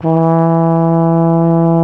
TENORHRN E 1.wav